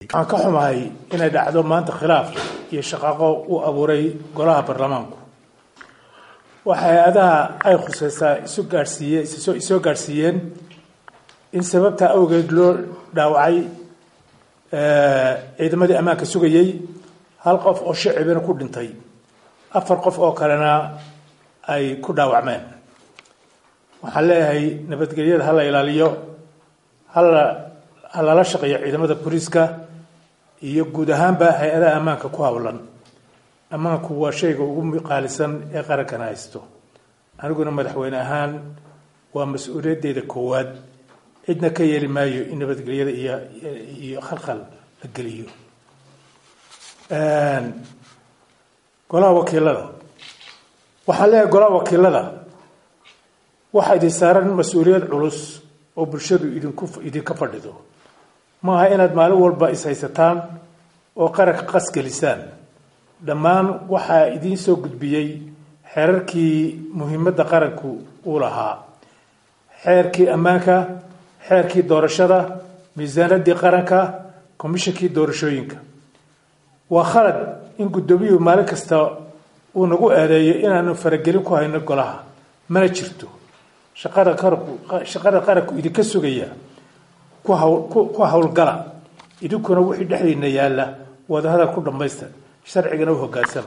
DHAGEYSO: Hadal uu Siilaanyo Jeediyay Murankii Golaha Wakiillada Kaddib
Hadal_uu_Siilaanyo_jeediyay_Buuqii_Golaha_Wakiillada_SL_kaddib_HOL.mp2